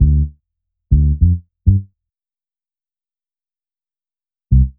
FK100BASS1-L.wav